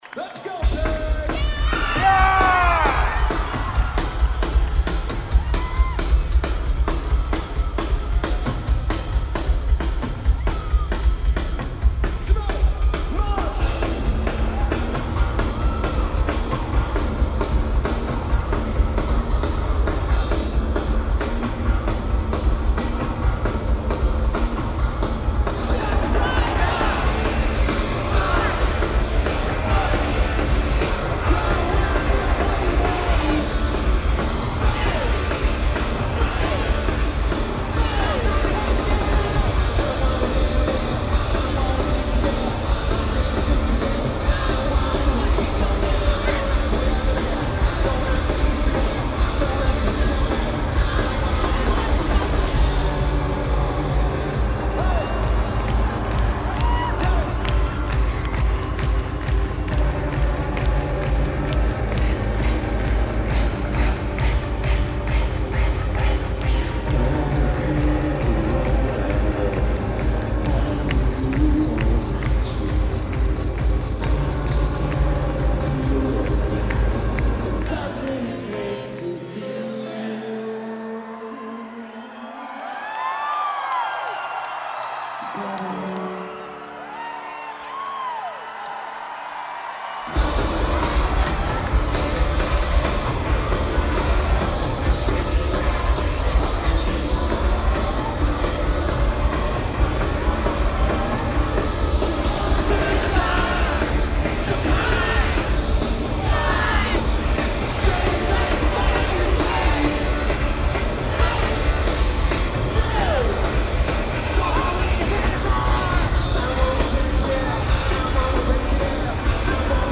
Kings Theatre
Lineage: Audio - AUD (CA14 Card + SP-SPSB-8-MKII + Zoom H1)